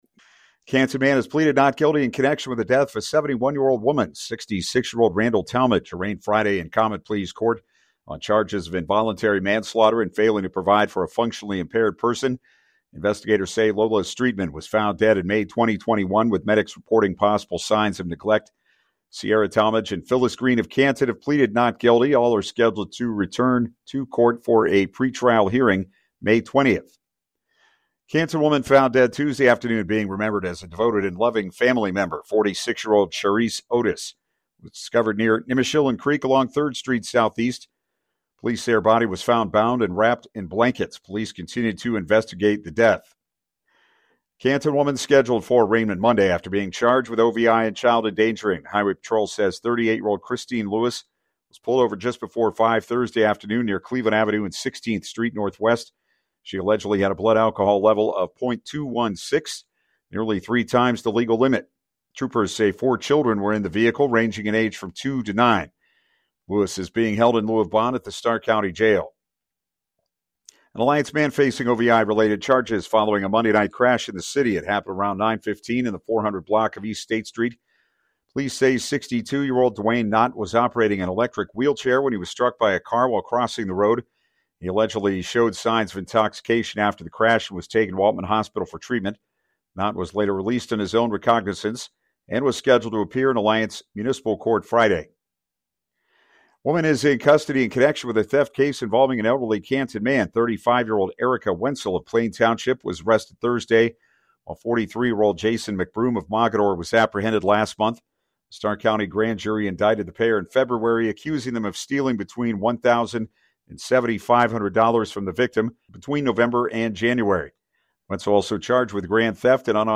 AFTERNOON-NEWS-1.mp3